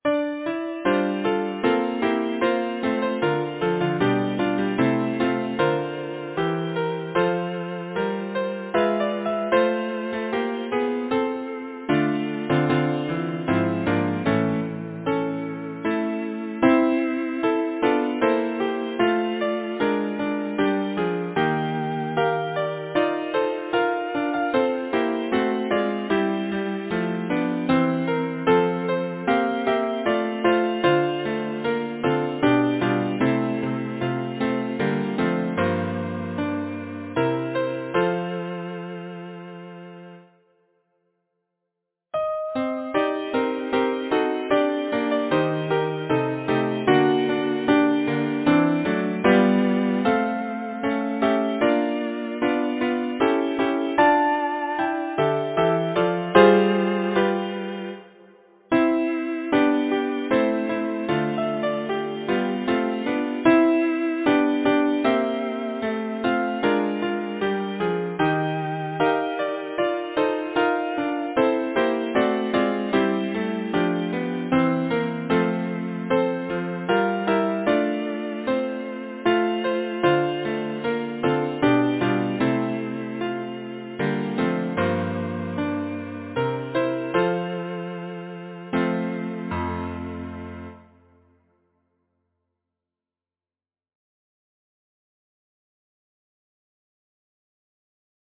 Title: Fain would I change that note Composer: John Ireland Lyricist: Tobias Hume Number of voices: 4vv Voicing: SATB Genre: Secular, Partsong
Language: English Instruments: A cappella